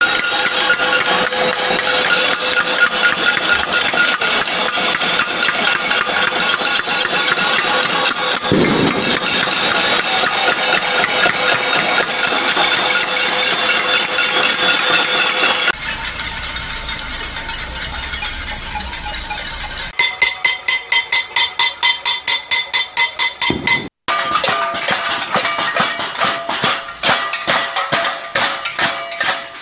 Caceroladas de diferente intensidad en Vitoria en apoyo al referéndum